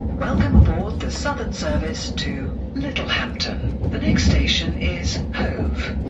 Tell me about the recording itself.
announcement recorded on a Class 377.